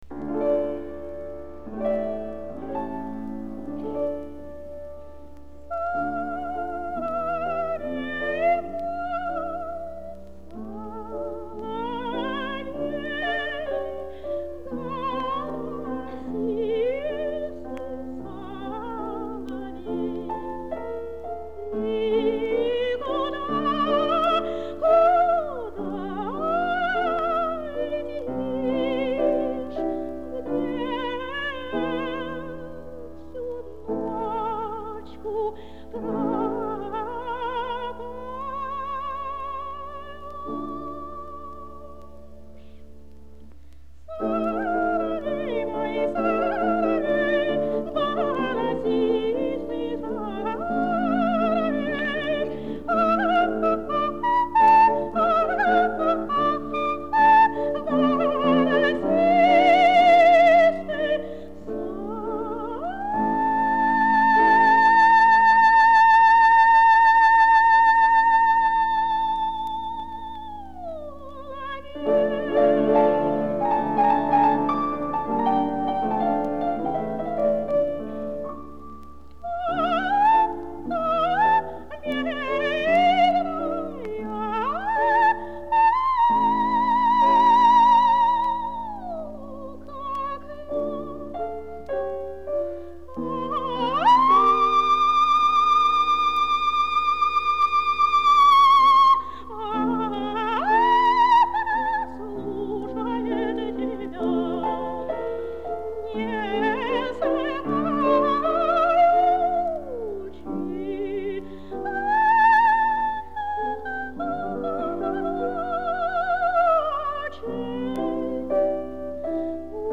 фортепьяно